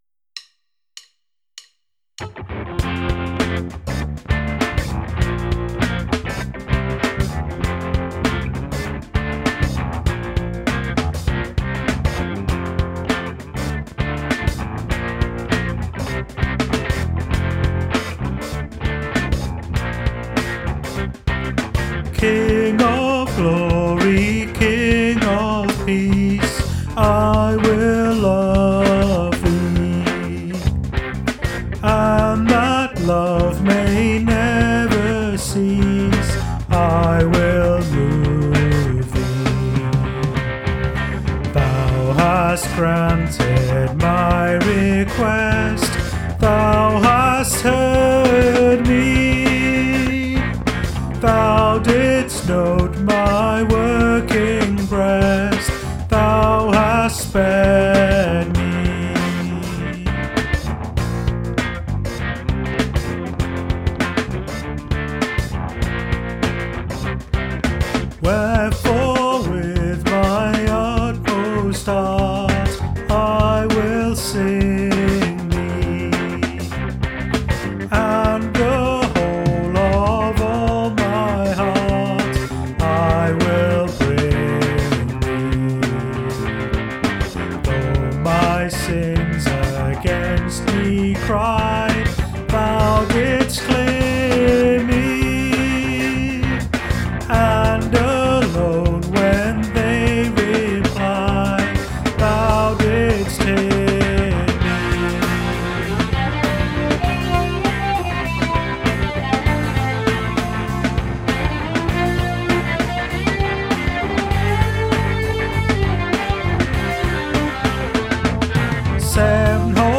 Modern arrangements of hymns from the 1933 edition of ‘The Methodist Hymn Book’.
These are all rough demos, don’t expect perfection!